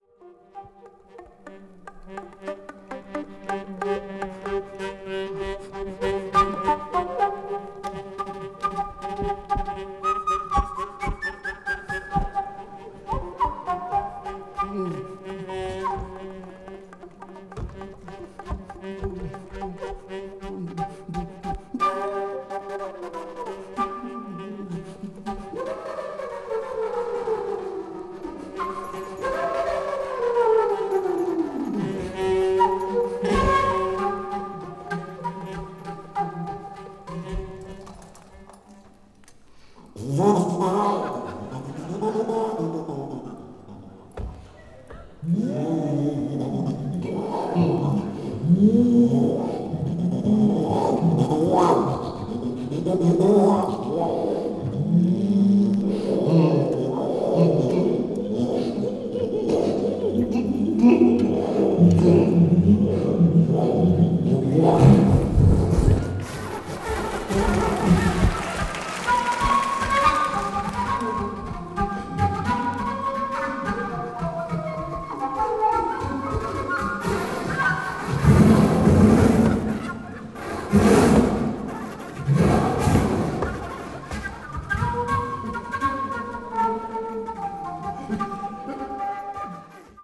The concert took place in Paris, France, in 1970.
ethnic jazz   modern jazz   post bop   soul jazz